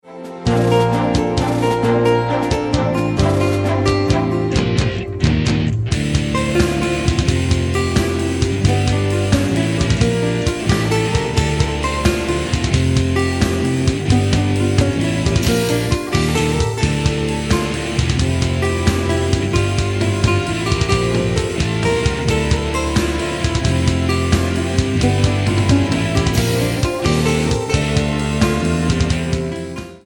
Guitar etc. , Lute , Progressive Metal